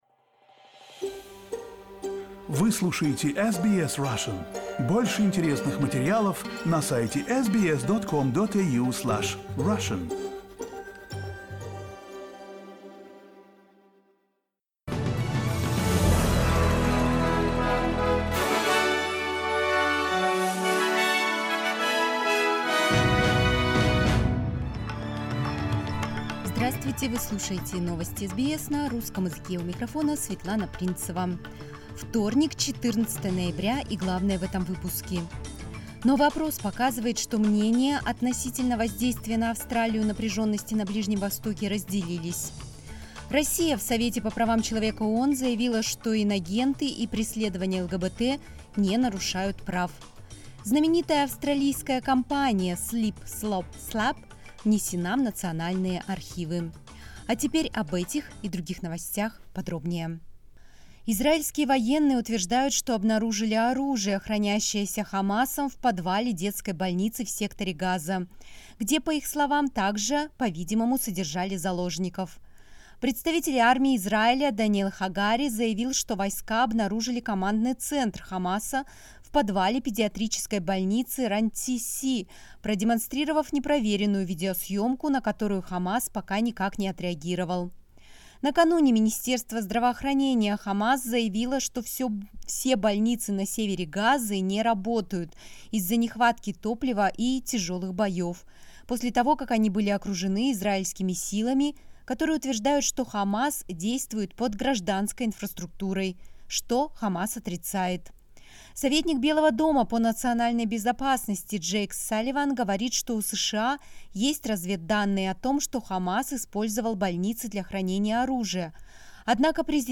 SBS news in Russian — 14.11.2023